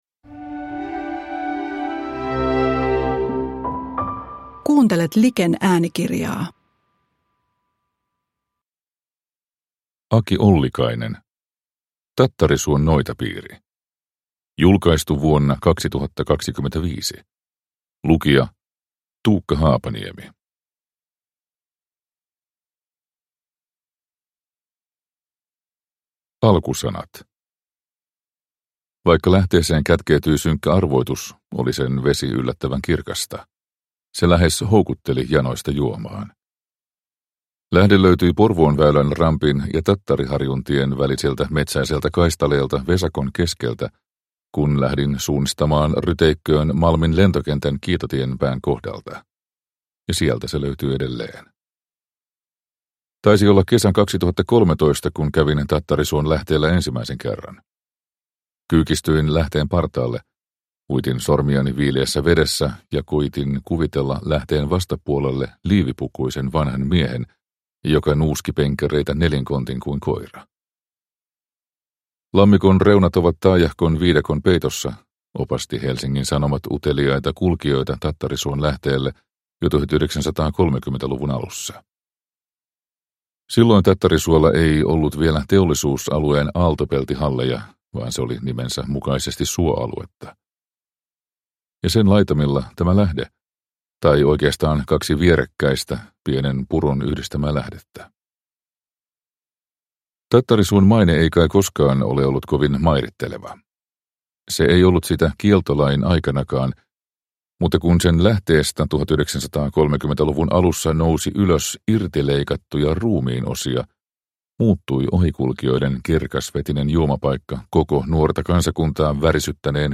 Tattarisuon noitapiiri – Ljudbok